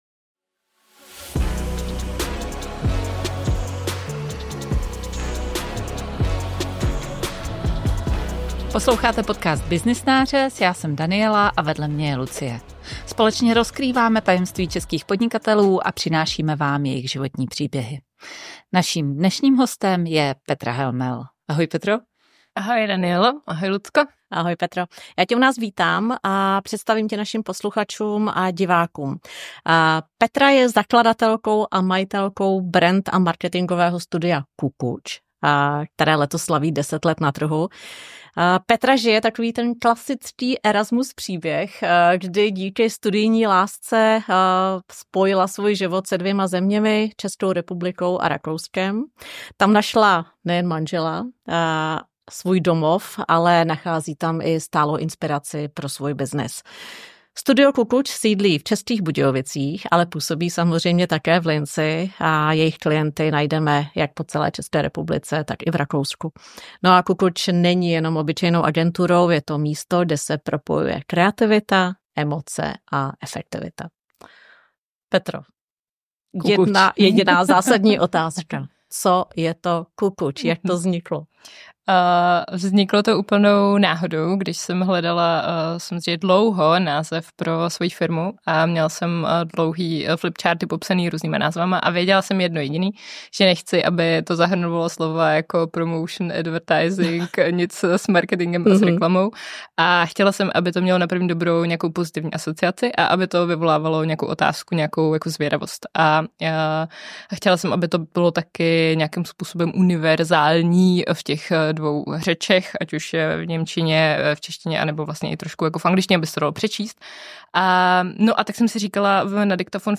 V tomhle rozhovoru najdete mix inspirace, reality checku i zajímavých vhledů do budoucnosti brandingu: ✅ Jak se mění práce s brandem v době AI?